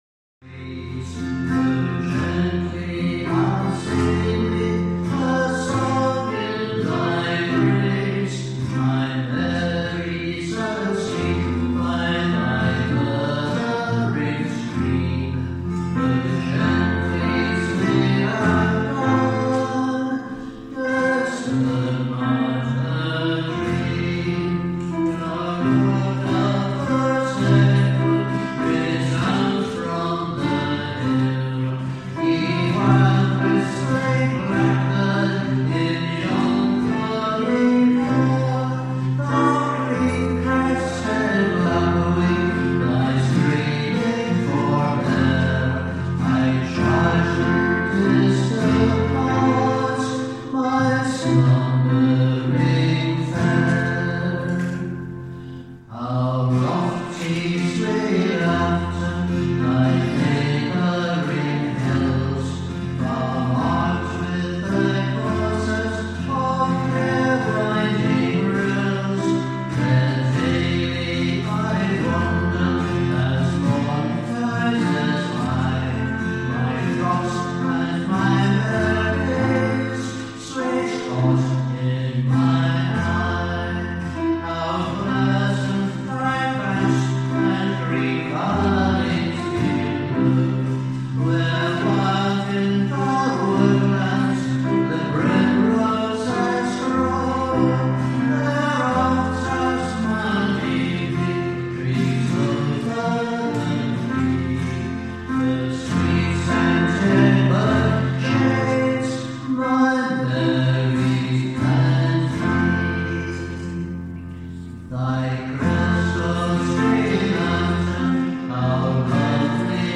Concerts with Band of Brothers and Family